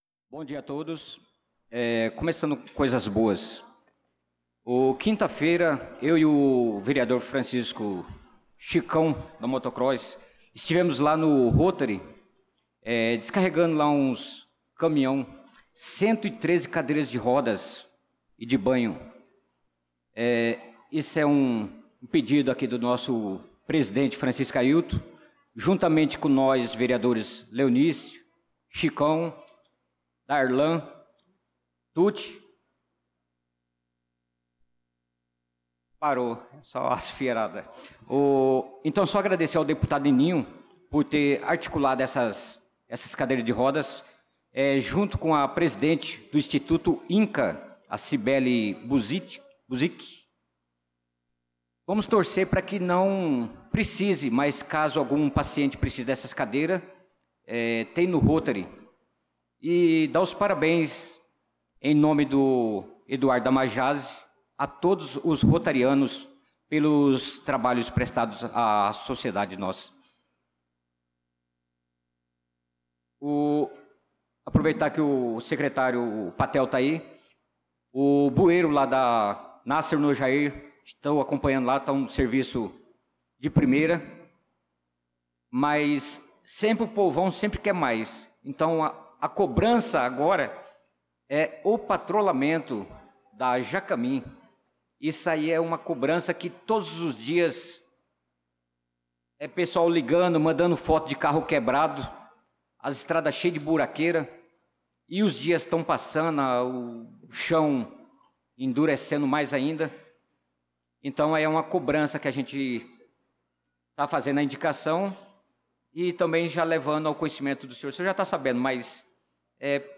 Pronunciamento do vereador Naldo da Pista na Sessão Ordinária do dia 16/06/2025.